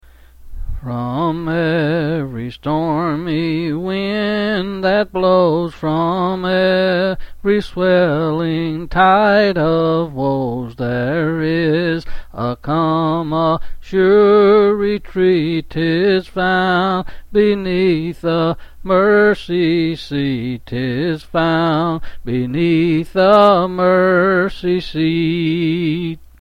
Quill Selected Hymn